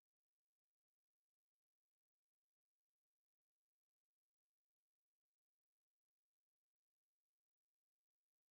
silent.mp3